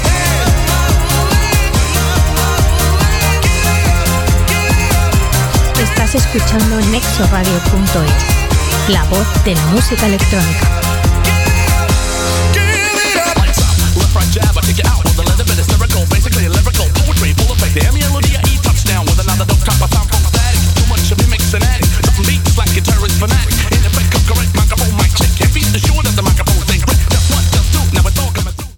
Tema musical i identificació de la ràdio